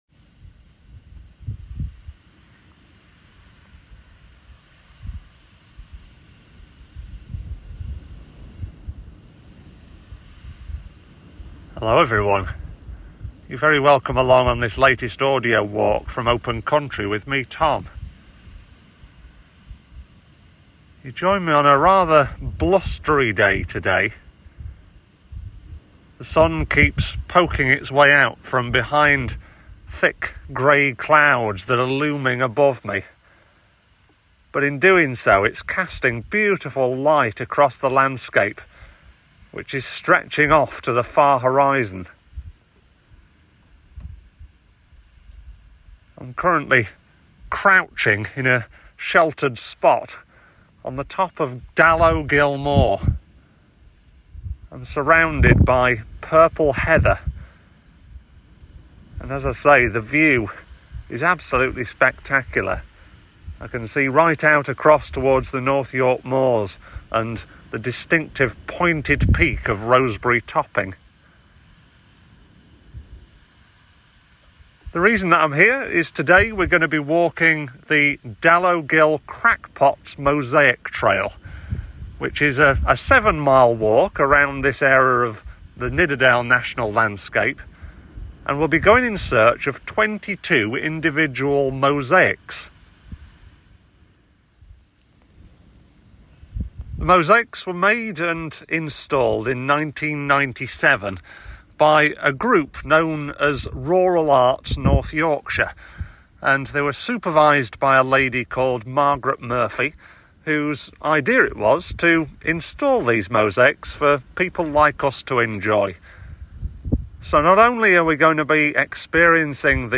Crackpots Mosaic Trail Audio Walk